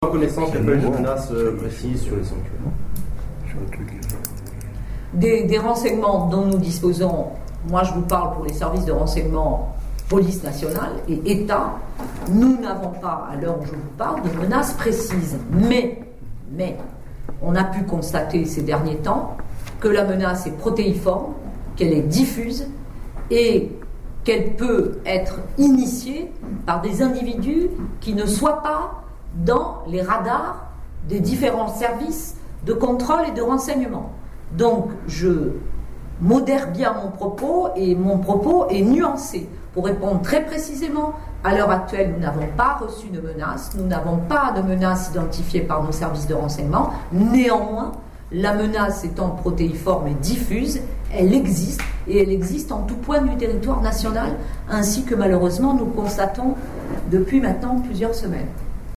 préfète des Hautes-Pyrénées a tenu une conférence de presse en mairie de Lourdes